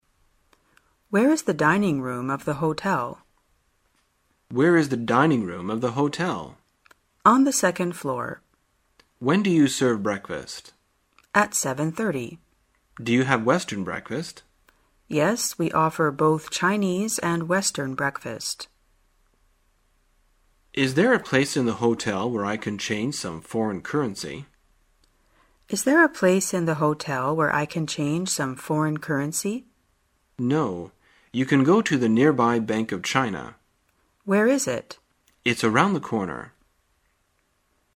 在线英语听力室生活口语天天说 第146期:怎样询问服务设施的听力文件下载,《生活口语天天说》栏目将日常生活中最常用到的口语句型进行收集和重点讲解。真人发音配字幕帮助英语爱好者们练习听力并进行口语跟读。